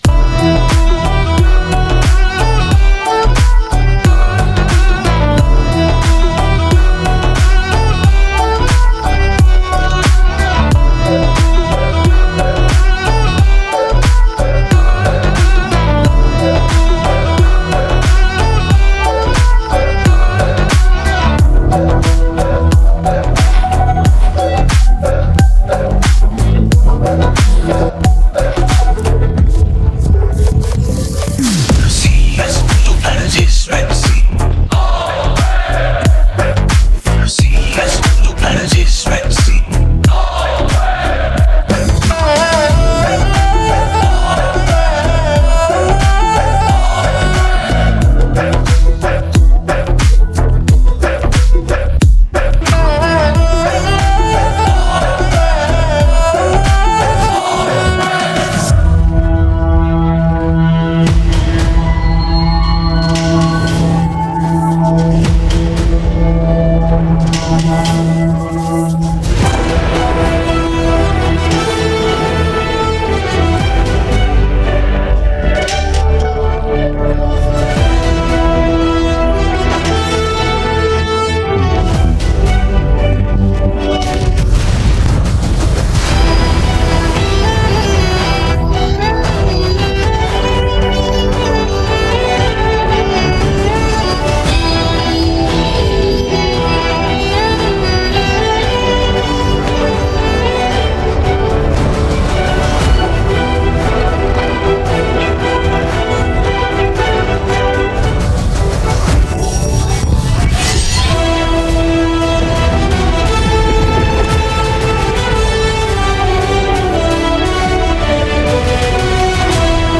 without dialogues and Fight Sounds
removed the dialogues and fight sounds as much as possible.